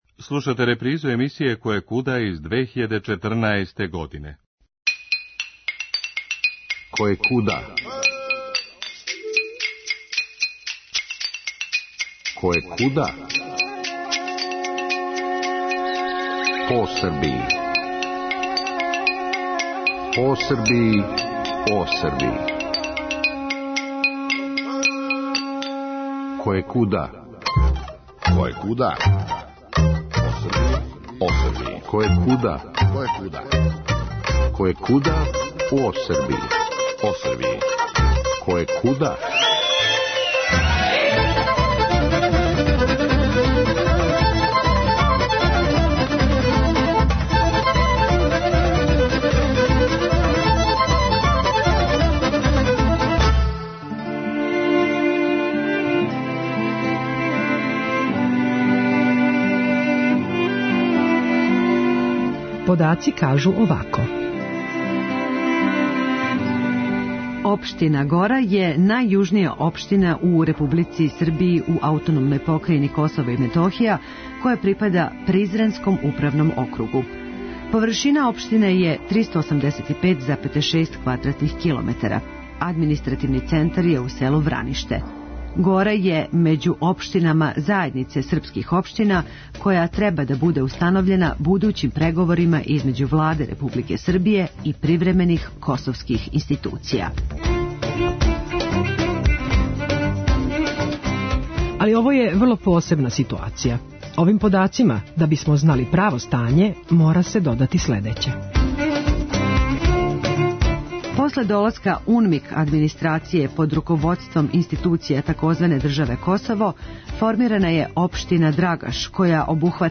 Али овог пута испричаћемо је како се једино могло - слушаћајући Горанце које смо посетили у Враништу, где је Административни центар и где постоје институције Републике Сербије..